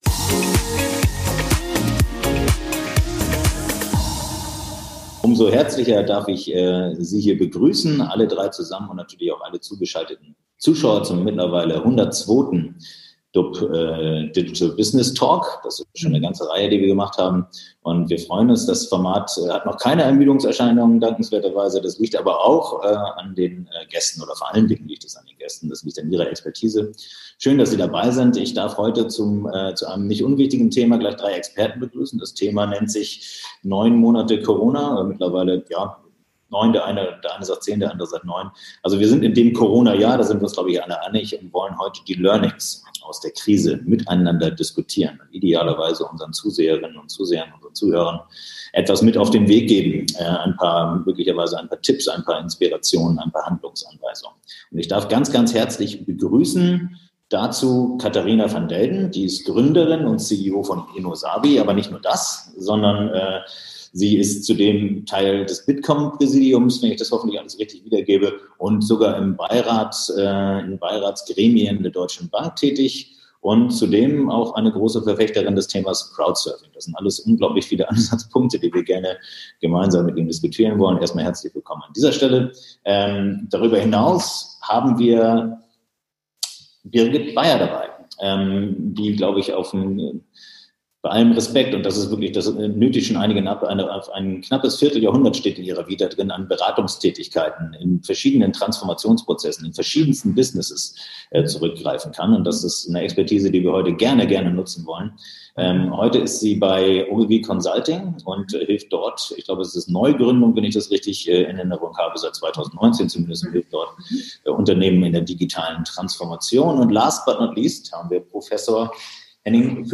Denn klar ist: Jedem Unternehmen, das sich traut, bietet sich derzeit die Chance zur Transformation. Vertreter vom HWWI, von Ogilvy Consulting und von innosabi berichten im DUB Digital Business Talk von ihren Erfahrungen der letzten Monate und konkreten Learnings für die Wirtschaft.